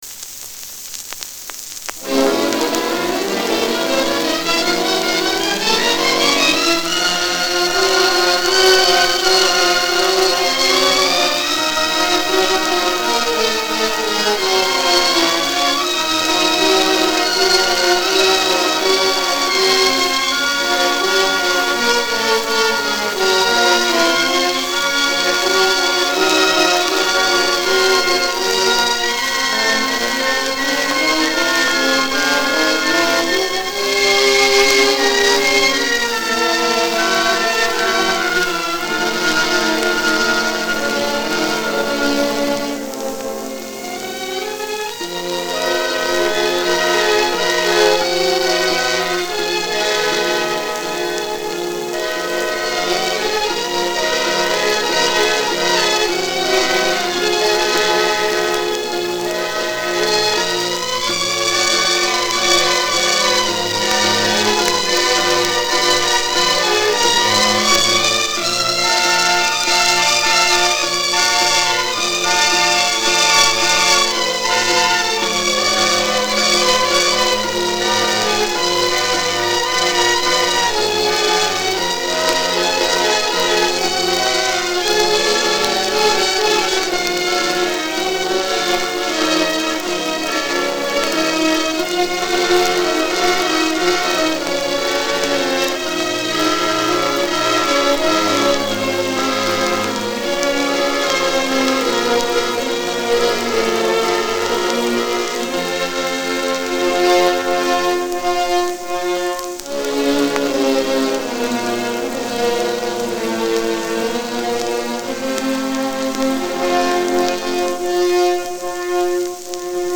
Описание: Эпическое симфоническое произведение.